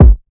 KICK (NC17)(1).wav